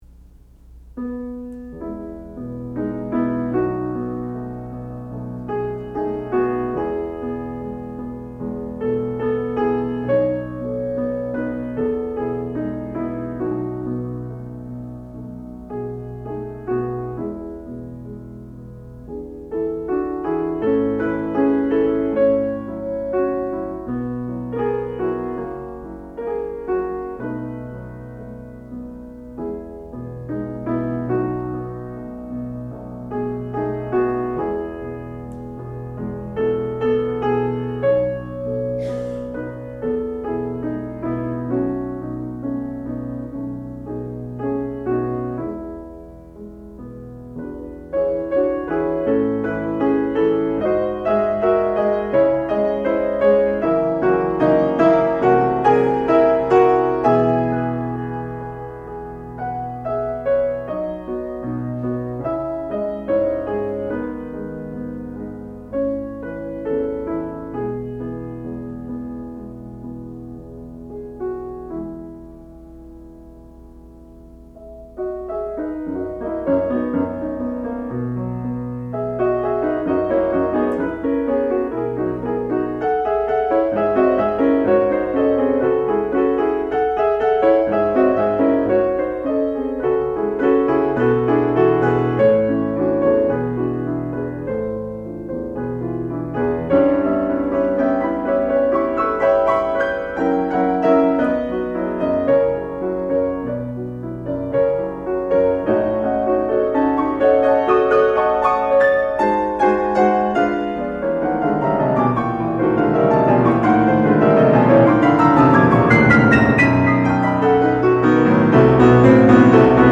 sound recording-musical
classical music
piano
Advanced Recital